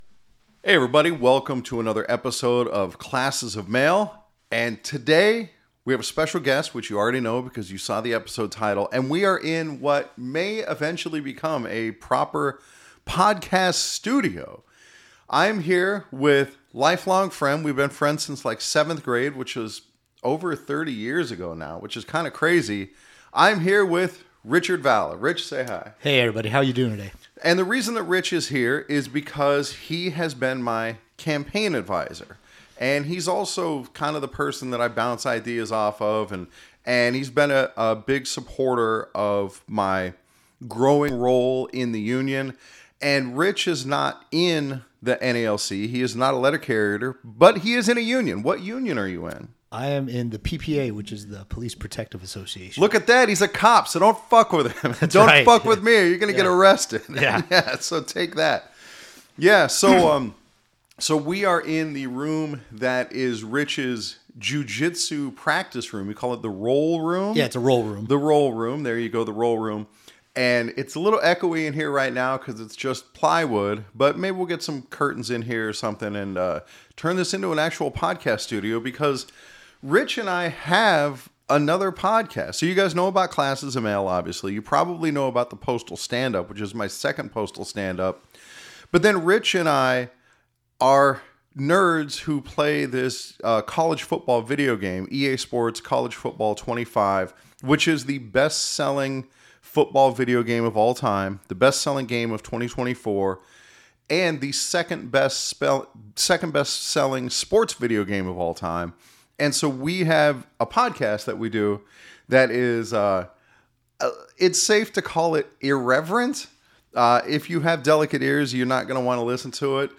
It's a great conversation, between two old friends.